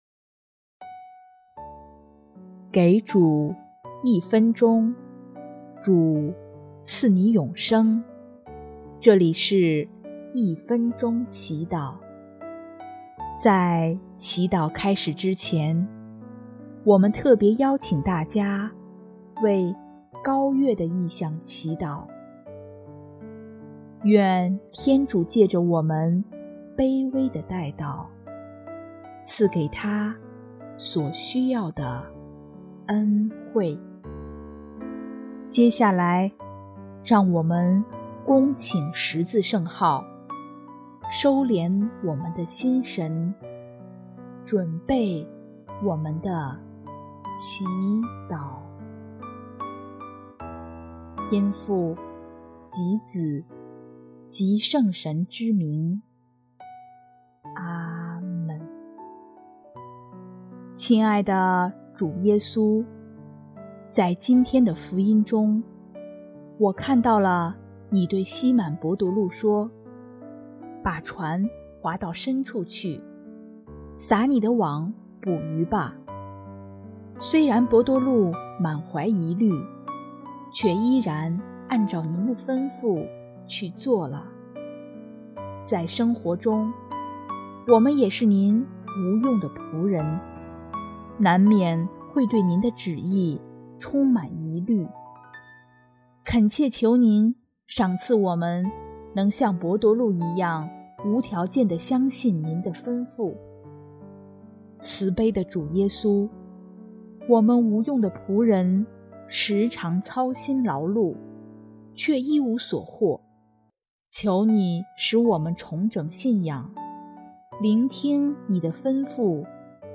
【一分钟祈祷】|9月7日 疑虑中选择信赖，化恐惧为勇气